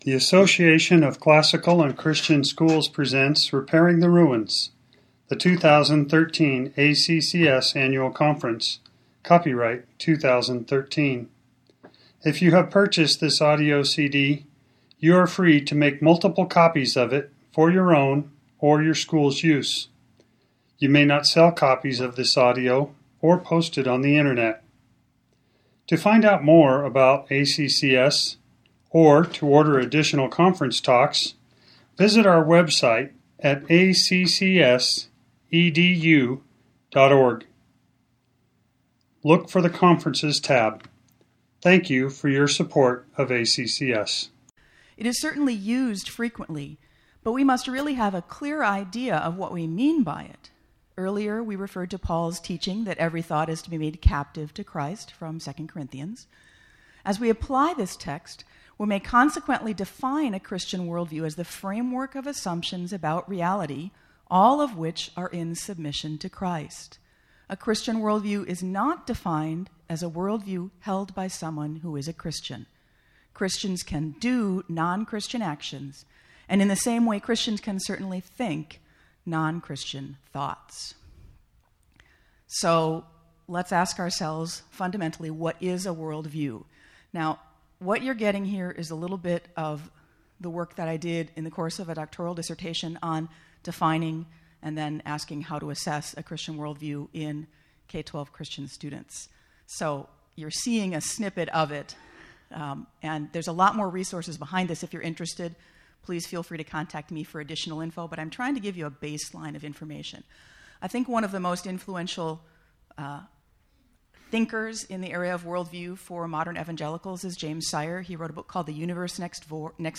2013 Workshop Talk | 0:33:46 | All Grade Levels, Virtue, Character, Discipline
The Association of Classical & Christian Schools presents Repairing the Ruins, the ACCS annual conference, copyright ACCS.